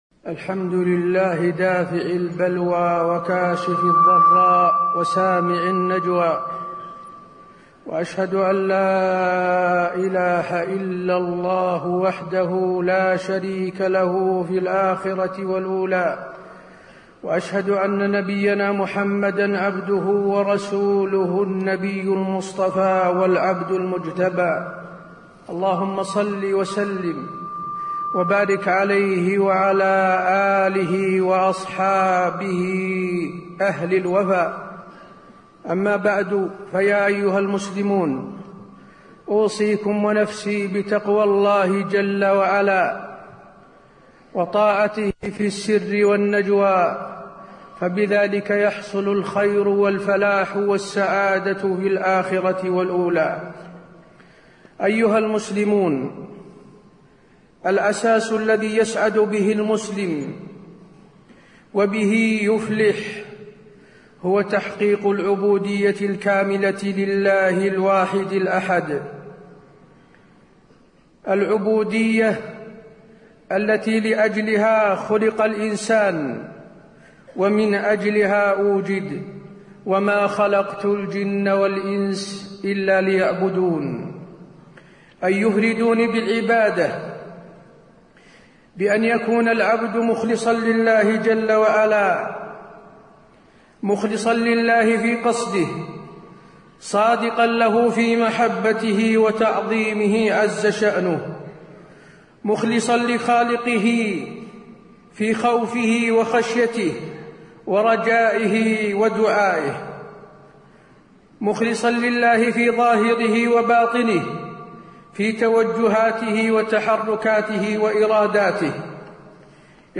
تاريخ النشر ٢٣ جمادى الآخرة ١٤٣٧ هـ المكان: المسجد النبوي الشيخ: فضيلة الشيخ د. حسين بن عبدالعزيز آل الشيخ فضيلة الشيخ د. حسين بن عبدالعزيز آل الشيخ حرص النبي على التوحيد